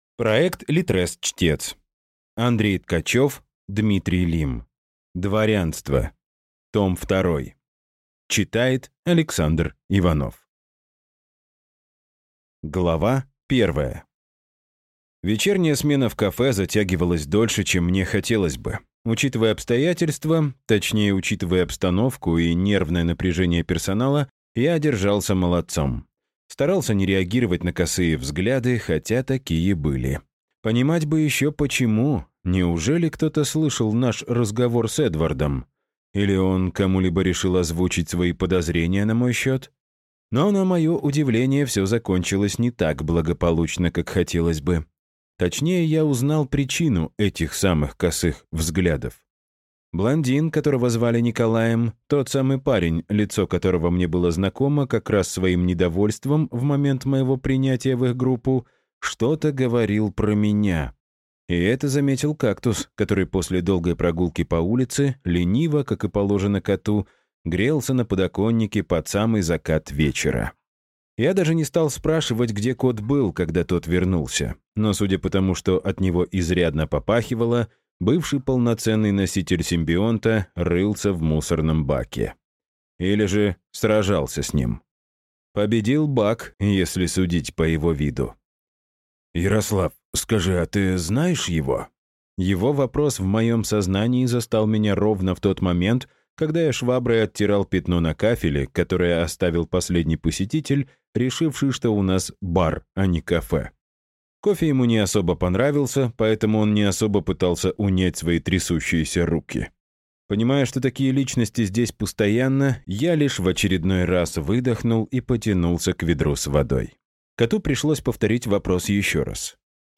Аудиокнига «Мама для мейн-куна».